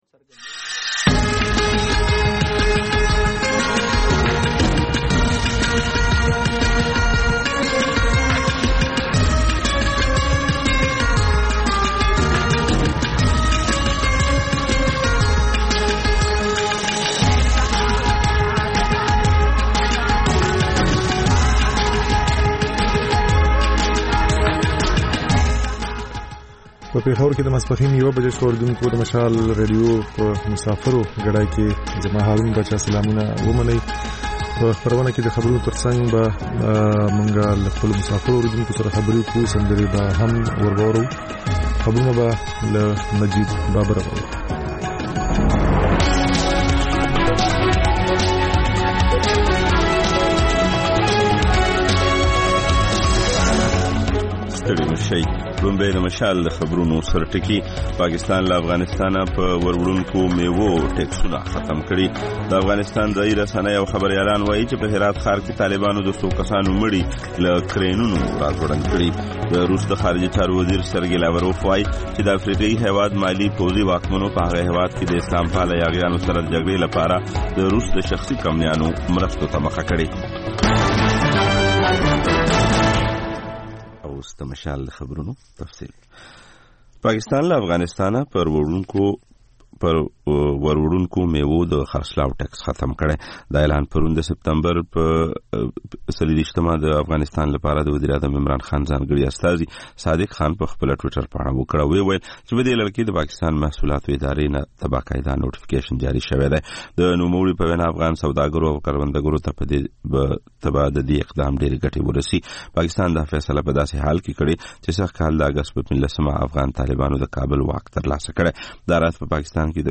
په دې خپرونه کې تر خبرونو وروسته بېلا بېل رپورټونه، شننې، مرکې خپرېږي.